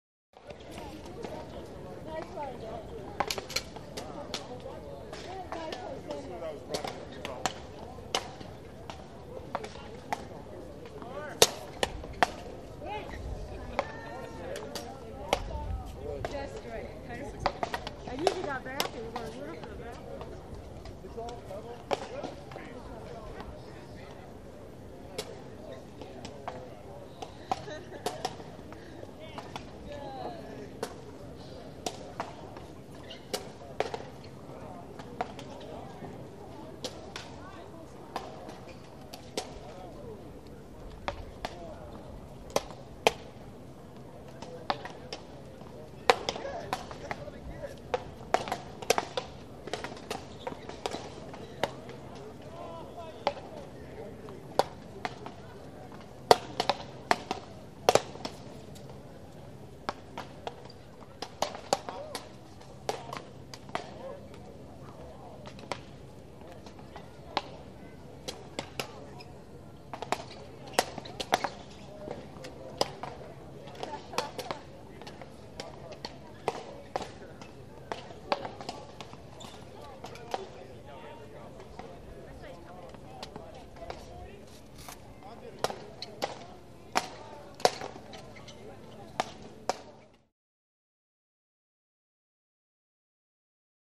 Sports-Paddleball | Sneak On The Lot
Beach Ambience - People Playing Paddleball